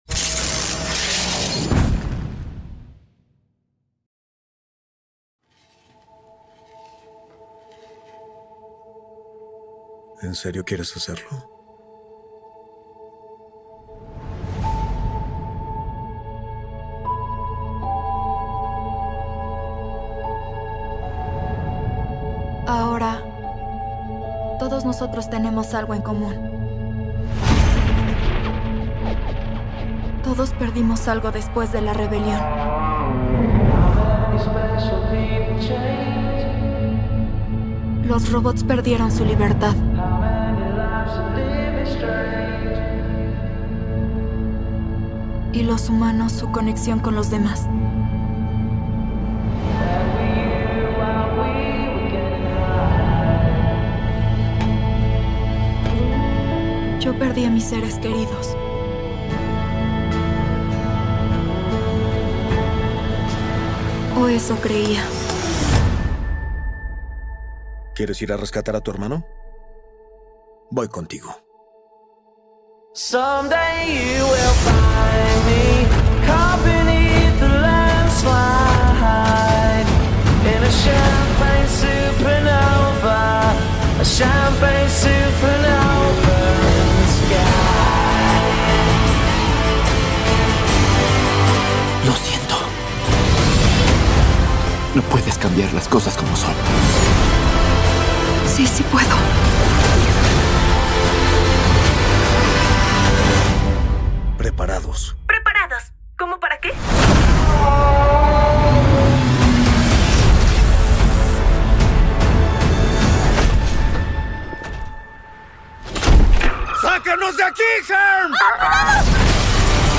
ESTADO ELÉCTRICO Tráiler Español Latino